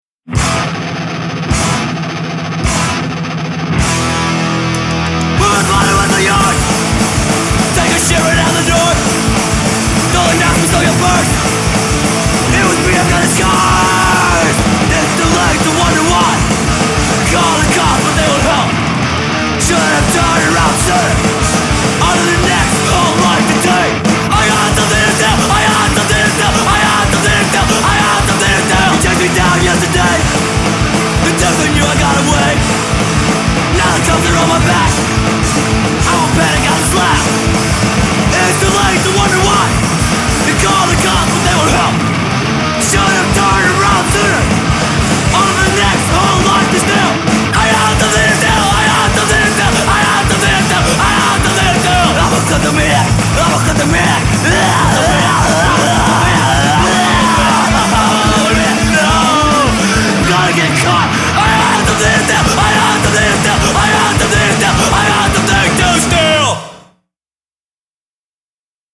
Classic 1980s style hardcore punk done correctly.
2003 demo